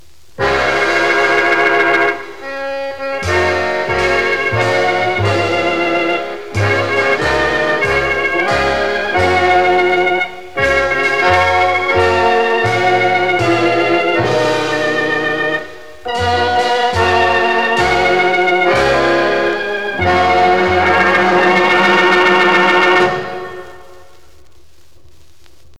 Don't try to correct me on the technical details because each of the six songs finish in an endless loop.